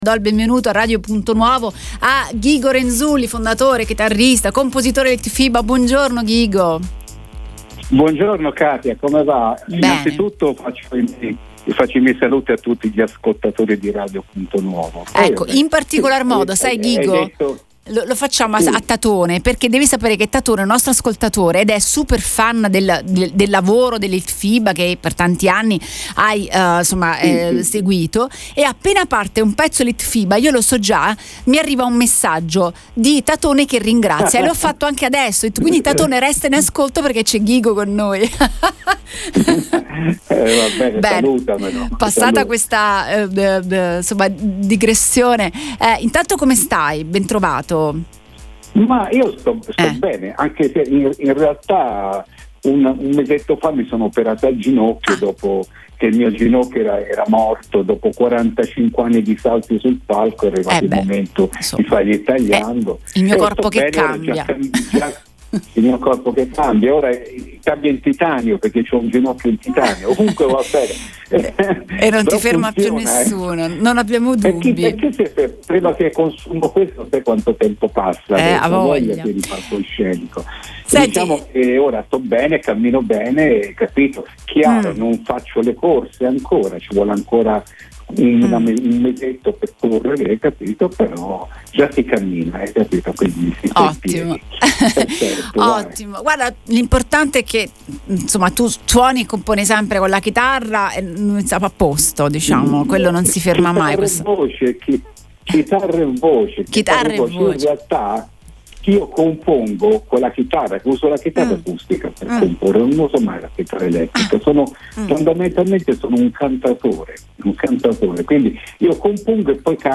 Ospite in diretta su Radio Punto Nuovo Ghigo Renzulli, cantautore irpino che ha presentato il suo nuovo album dal titolo “Diddy”.